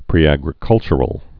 (prēăg-rĭ-kŭlchər-əl)